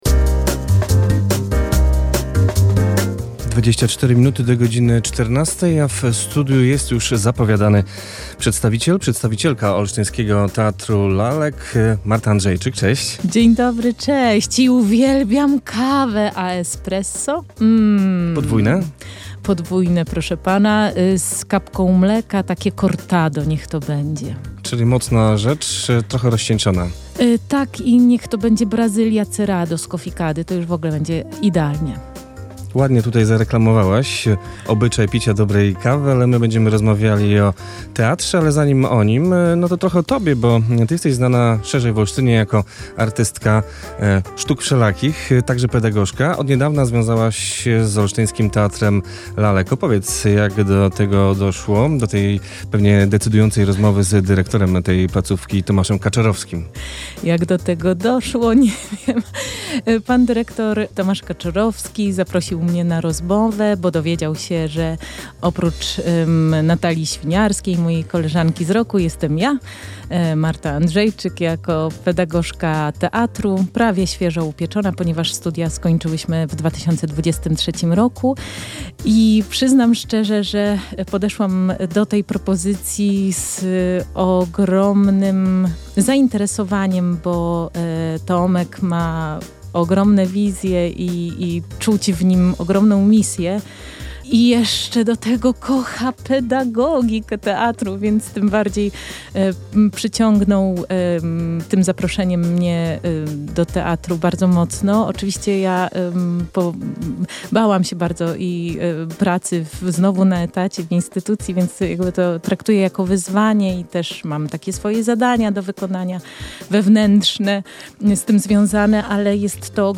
W radiowym studiu opowiedziała, czym zajmuje się w kulturalnej placówce.